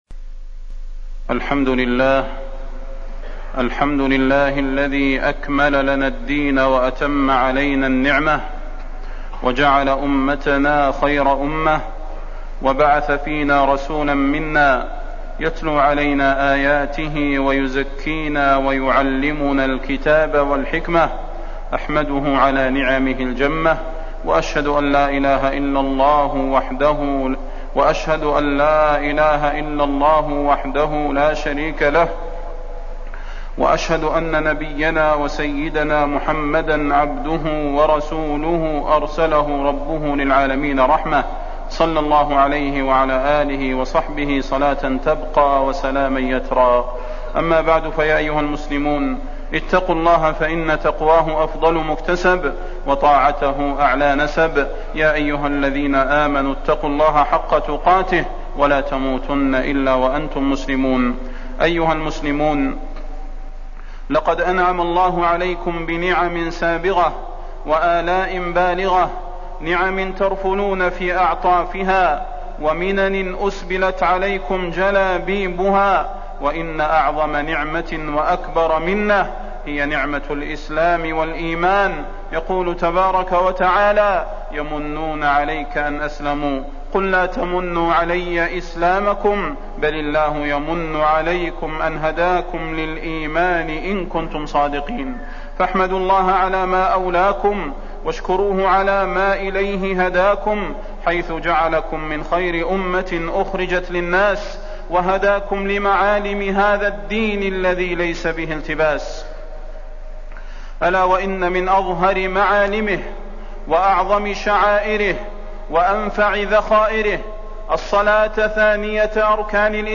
تاريخ النشر ٤ صفر ١٤٣٠ هـ المكان: المسجد النبوي الشيخ: فضيلة الشيخ د. صلاح بن محمد البدير فضيلة الشيخ د. صلاح بن محمد البدير الصلاة